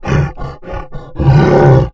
MONSTER_Exhausted_05_mono.wav